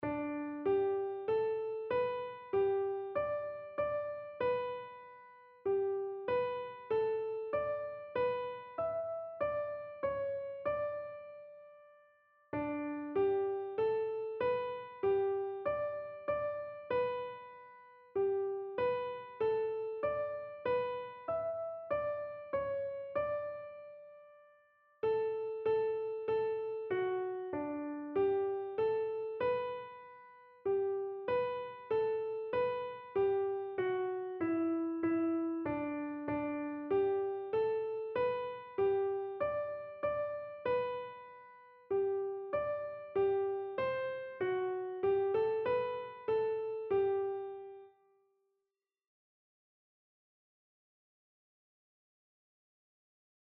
Einzelstimmen (Unisono)
• Sopran [MP3] 836 KB